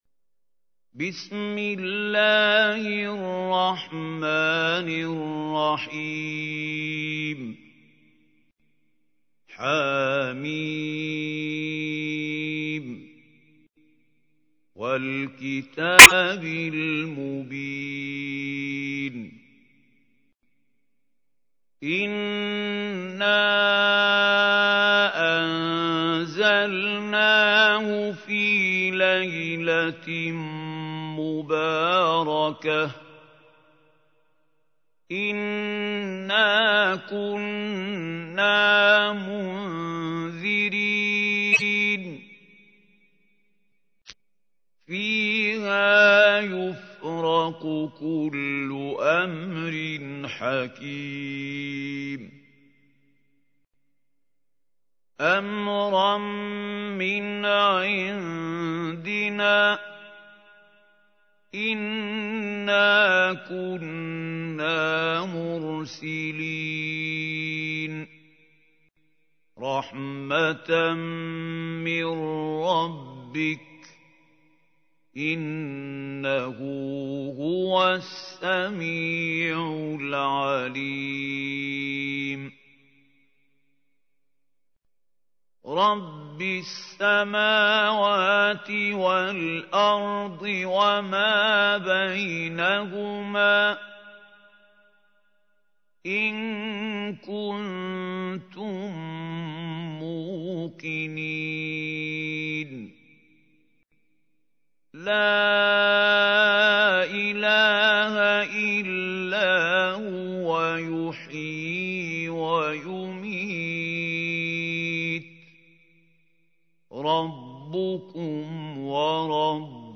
تحميل : 44. سورة الدخان / القارئ محمود خليل الحصري / القرآن الكريم / موقع يا حسين